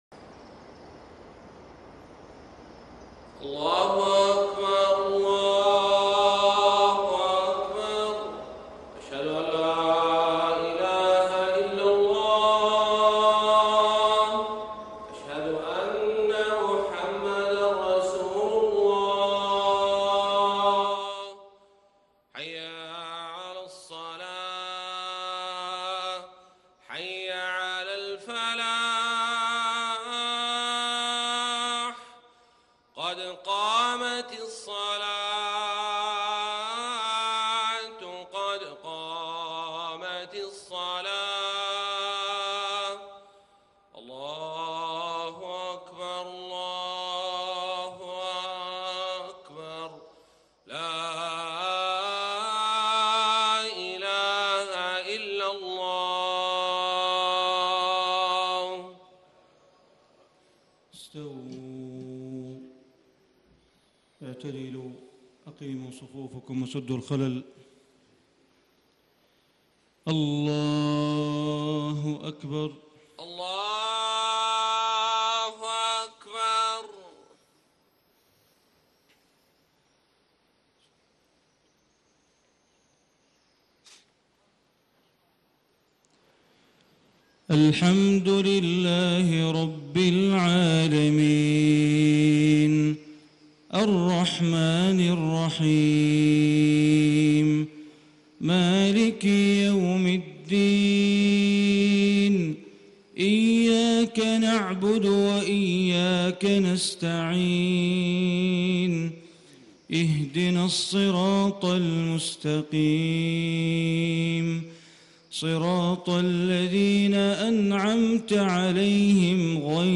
صلاة الفجر 6-2-1435 من سورة يوسف > 1435 🕋 > الفروض - تلاوات الحرمين